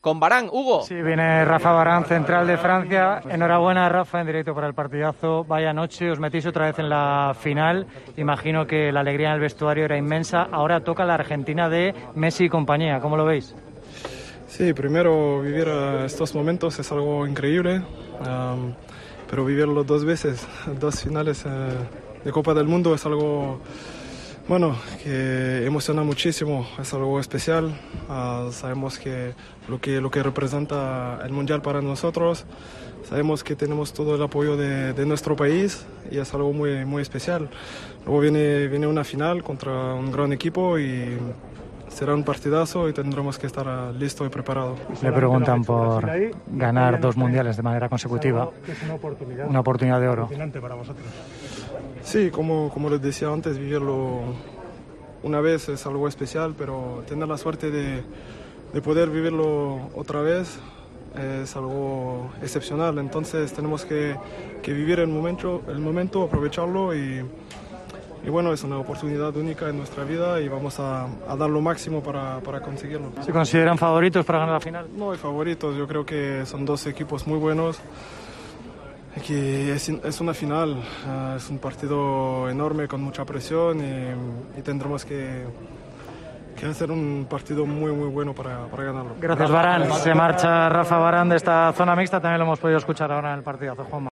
habló en la zona mixta al micrófono